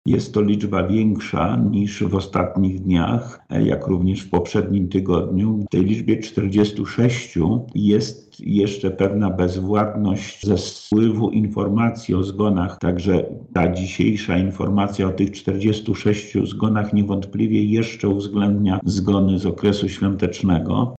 • mówi wojewoda lubelski Lech Sprawka.